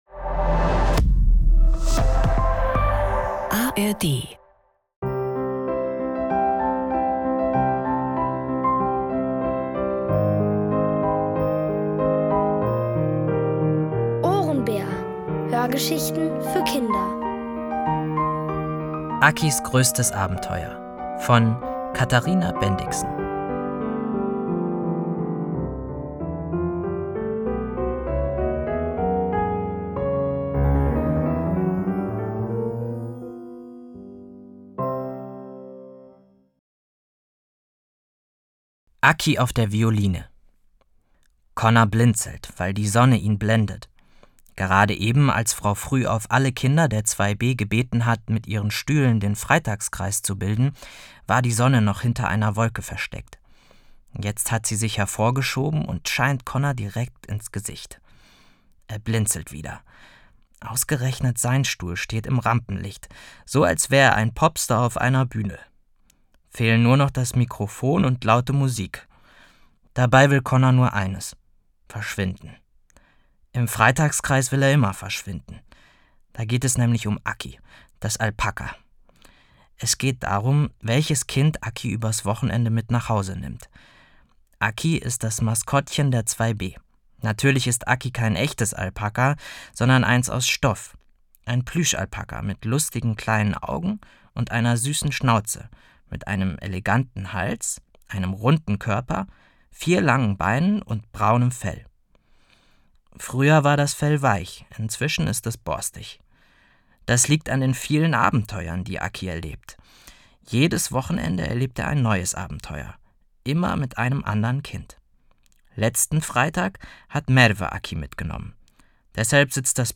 Akis größtes Abenteuer | Die komplette Hörgeschichte! ~ Ohrenbär Podcast